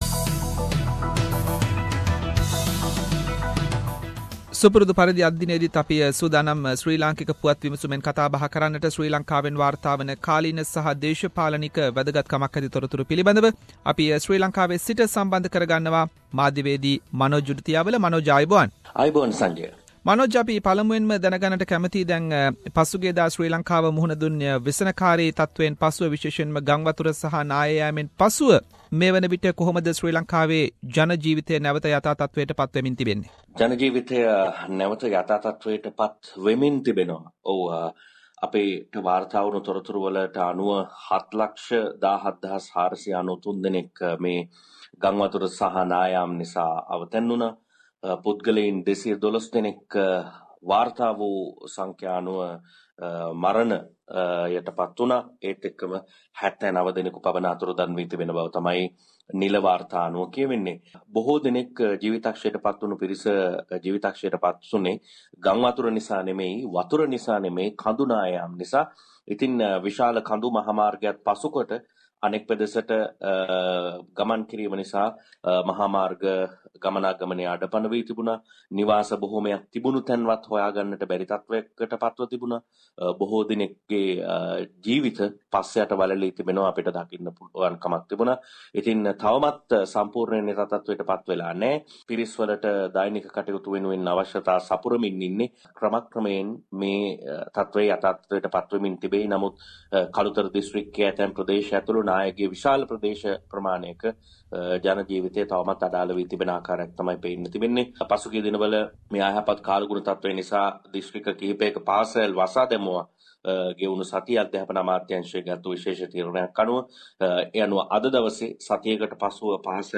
SBS Sinhalese weekly Sri Lankan news wrap, With prominent Sri Lankan current affairs and political issues.